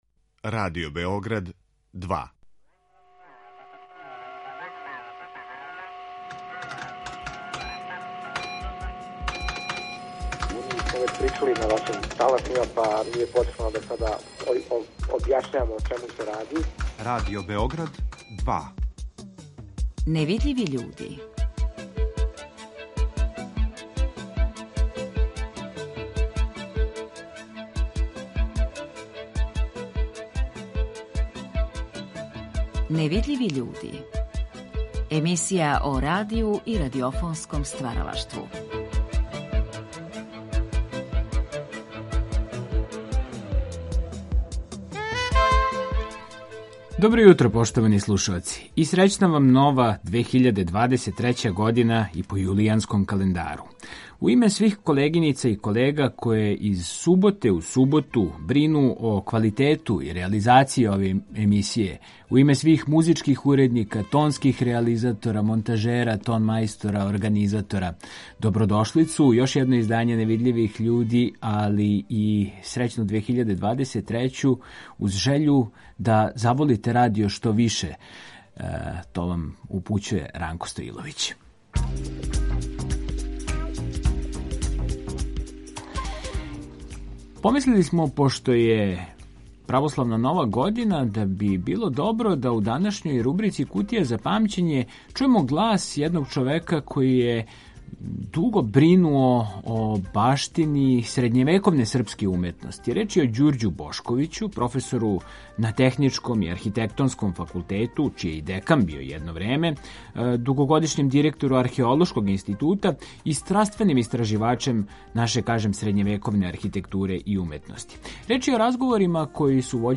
Ови разговори вођени су за циклус емисија „Гост Другог програма".